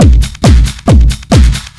ELECTRO 09-L.wav